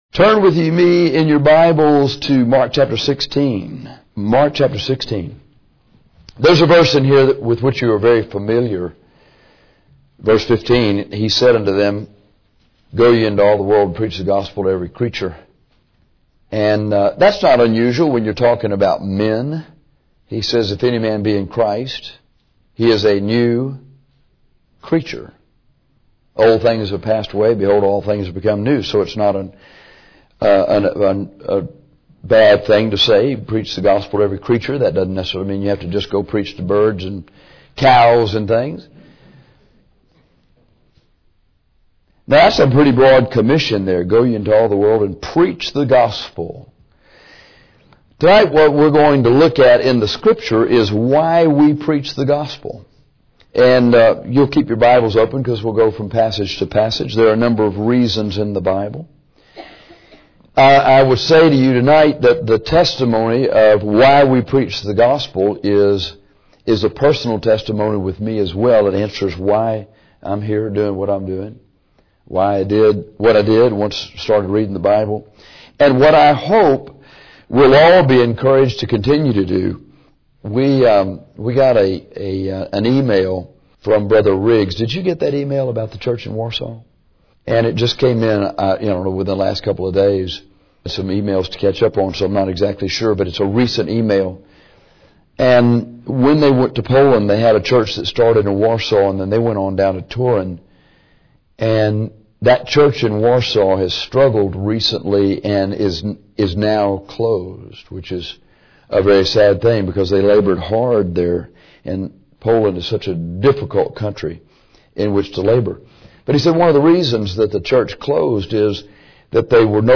Why Preach the Gospel, Mark 16:15 - Bible Believers Baptist Church